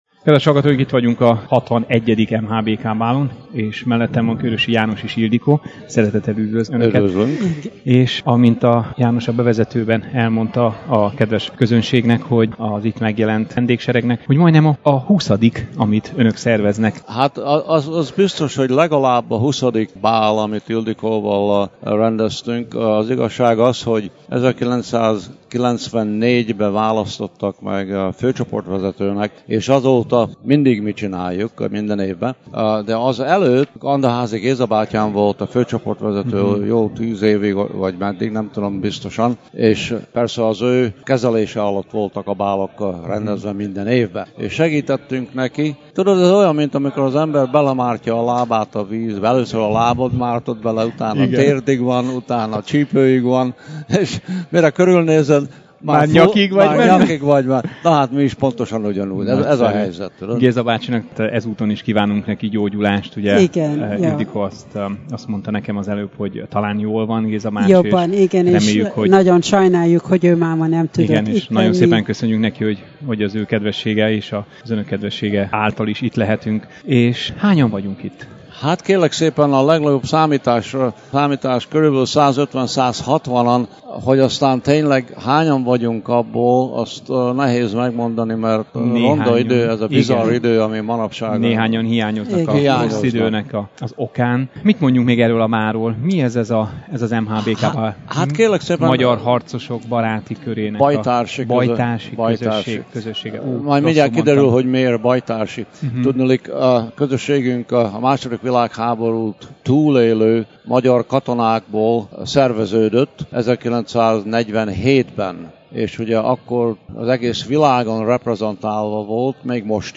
Beszámoló a 2014-es clevelandi MHBK bálról – Bocskai Rádió
interjú közben